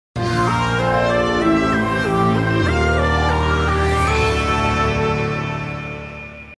fanfare
Category:Sound effects